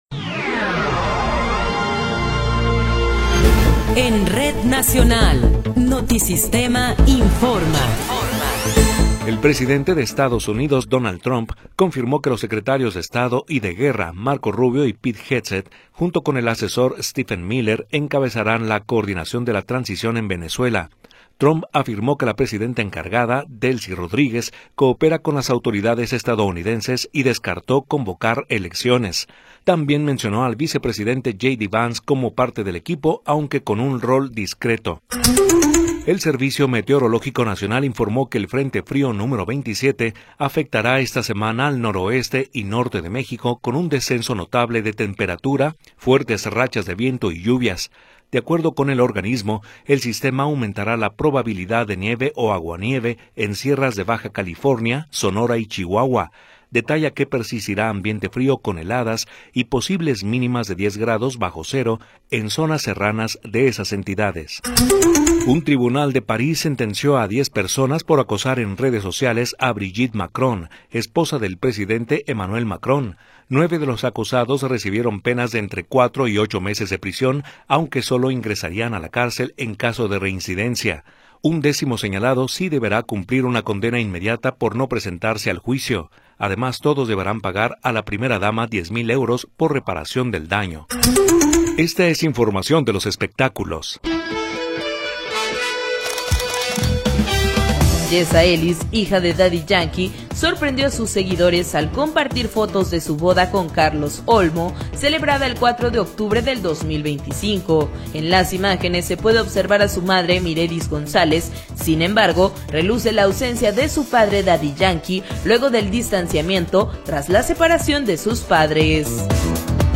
Noticiero 19 hrs. – 5 de Enero de 2026
Resumen informativo Notisistema, la mejor y más completa información cada hora en la hora.